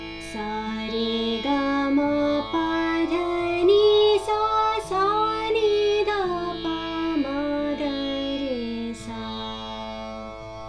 The solfege syllables Sa-Ri-Ga-Ma-Pa-Da-Ni-Sa are used for all scales.
Raag Bhairav (heptatonic)
It is a morning raga, and solemn peacefulness is its ideal mood.